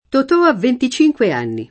venticinque [venti©&jkUe] num.